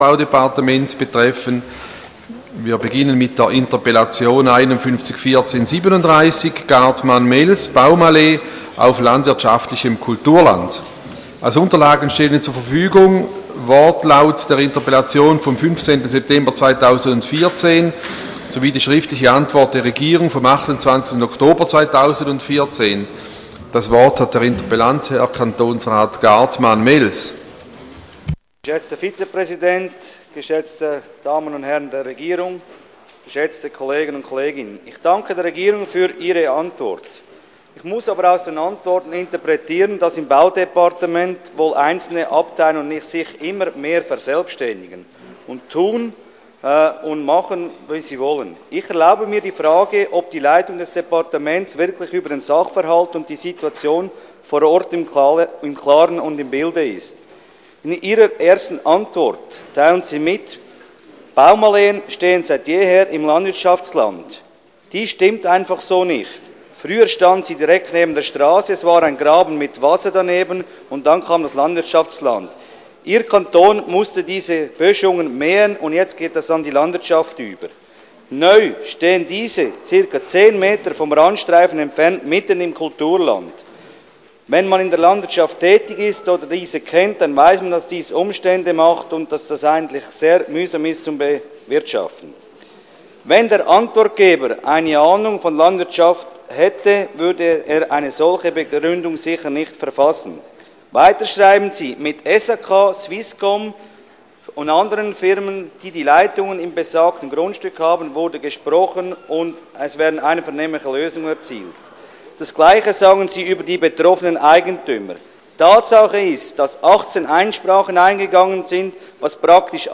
25.2.2015Wortmeldung
Session des Kantonsrates vom 23. bis 25. Februar 2015